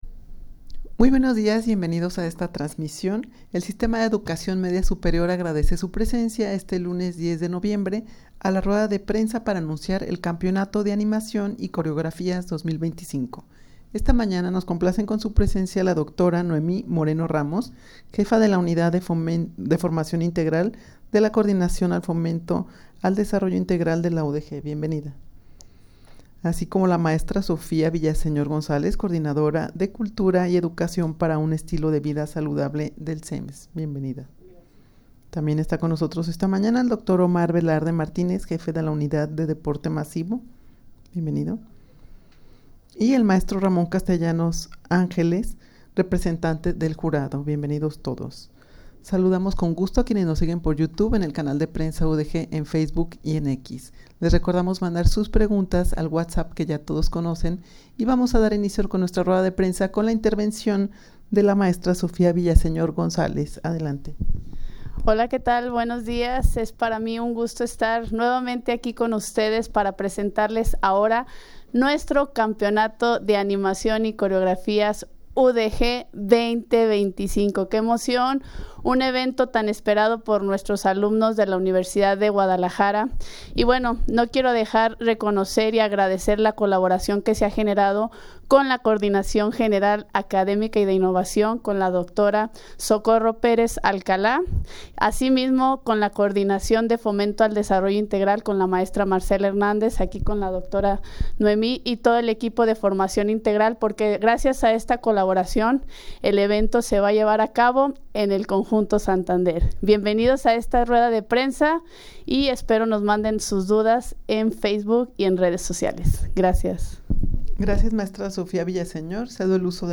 Audio de la Rueda de Prensa
rueda-de-prensa-para-anunciar-el-campeonato-de-animacion-y-coreografias-2025.mp3